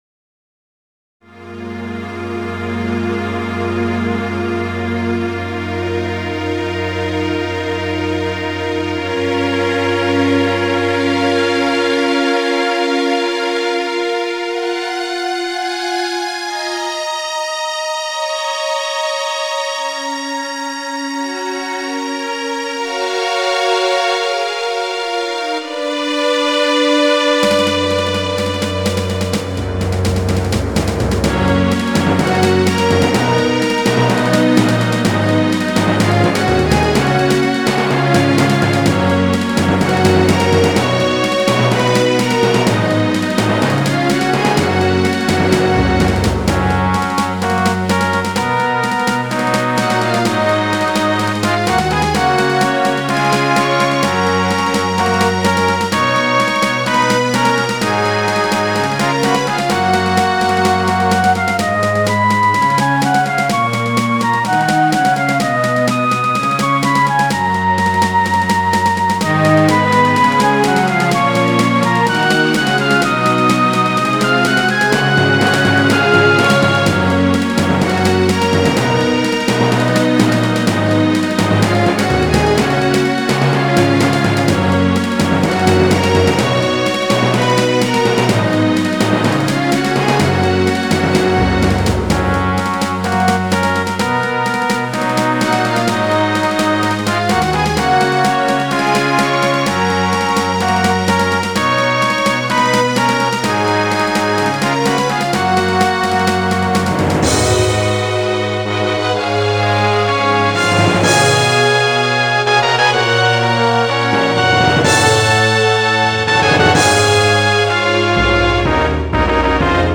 壮大すぎてパンポット・和音の耳コピが大変だった。GS音源。